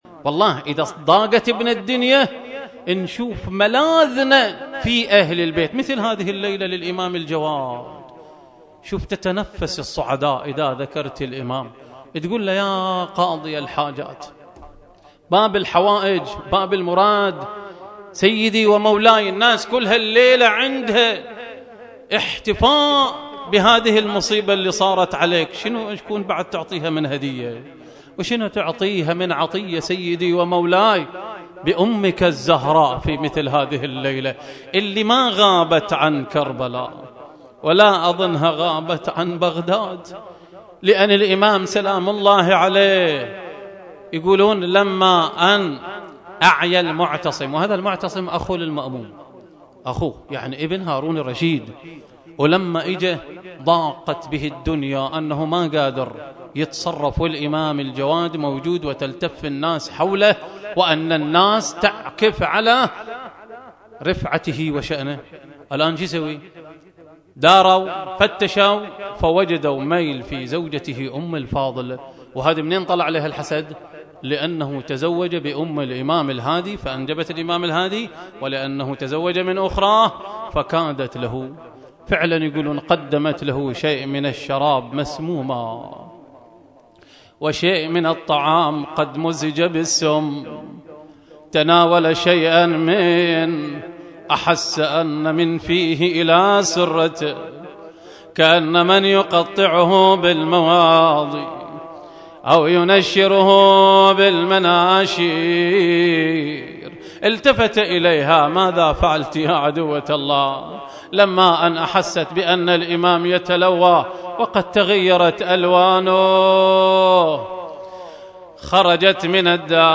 ll مأتم الغربي ll مجلس أحياء وفاة الامام الجواد علية السلام 1433هـ
أحيا ماتم النعيم الغربي وفاة الامام محمد الجواد علية السلام (( باب الحوائج )) مساء امس الاثنين